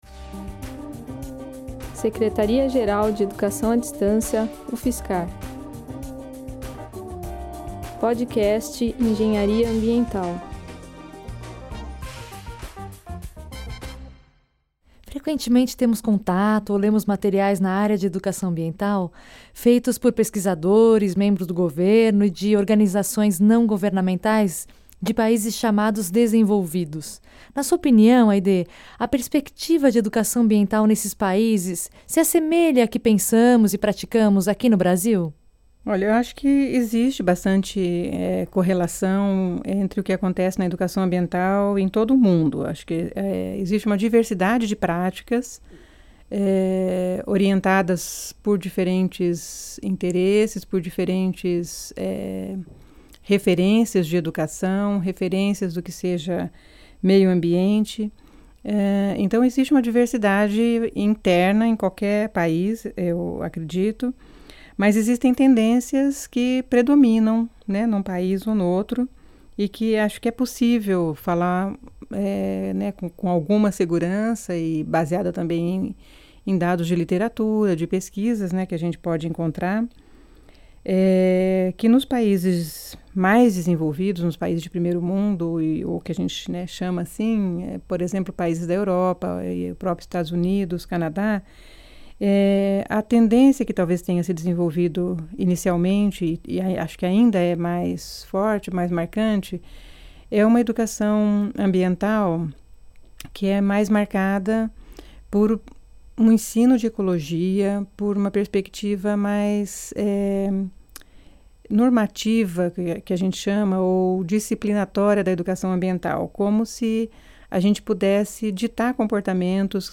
Na segunda parte da entrevista a professora convidada esclarece que há uma tendência da EA comportamental prevalecer entre os países “desenvolvidos” (Europa, EUA e Canadá). Em contraste, a EA caracterizada como comunitária, emancipatória e/ou transformadora, tende a ser mais presente no Brasil e nos demais países da América Latina. A entrevistada destaca também que o papel fundamental da EA é evidenciar a complexidade envolvida na questão ambiental, assim como transformar a relação entre os seres humanos e a natureza.